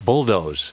Prononciation du mot bulldoze en anglais (fichier audio)
Prononciation du mot : bulldoze